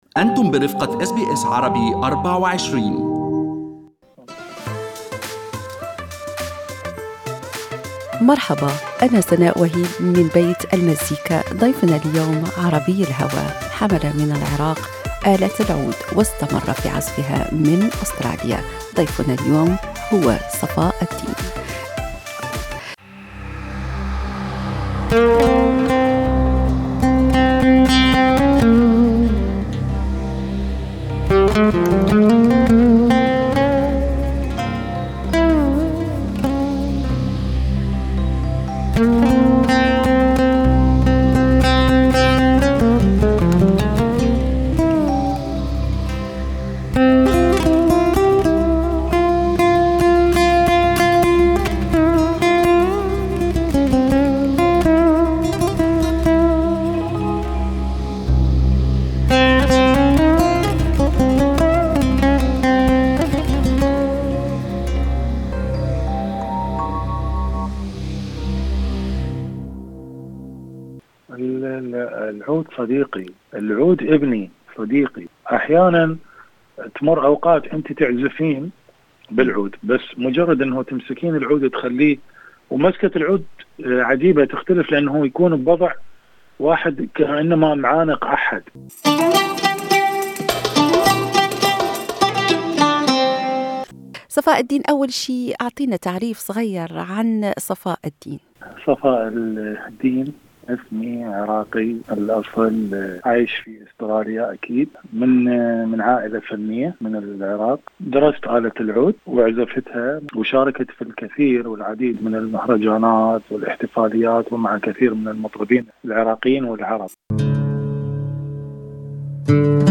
Oud Player